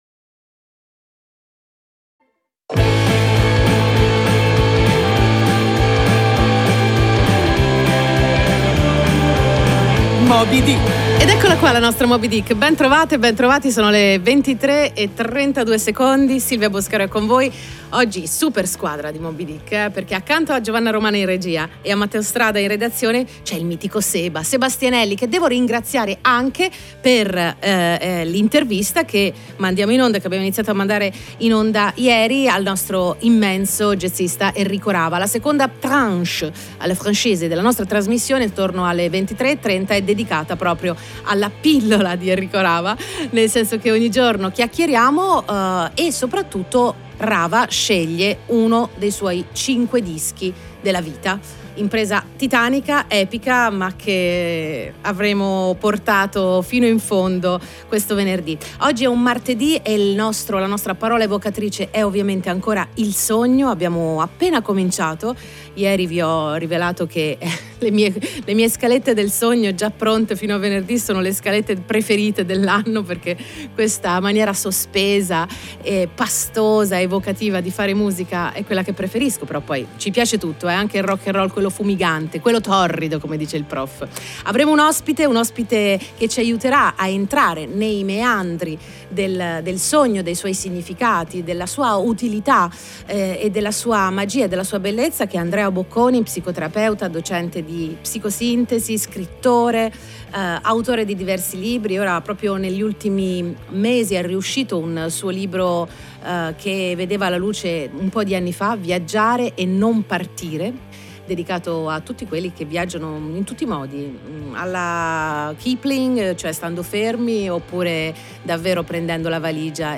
Ascolta la registrazione della trasmissione in formato MP3.